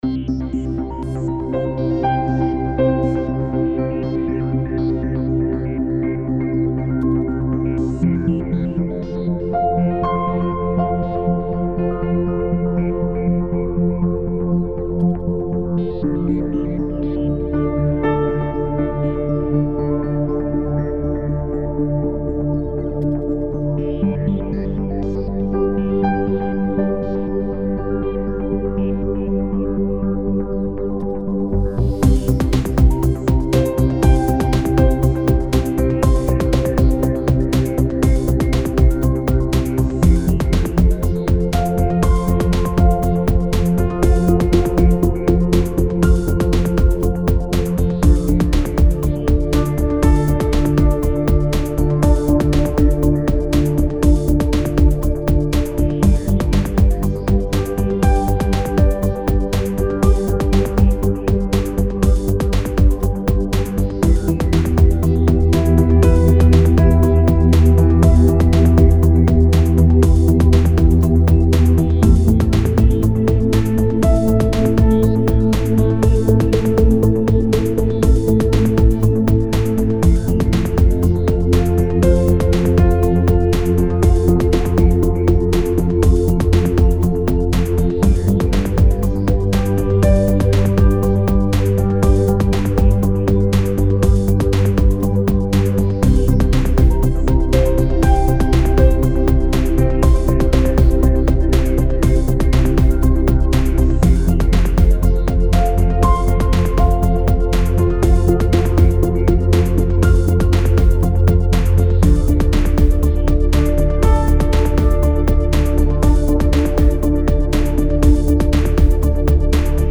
🧠 Corporate Sound B2B
👉 Vertrauen durch Klang.
Verbindlich.
Strukturiert.
Präzise.
So klingt moderner Corporate Sound für Unternehmen.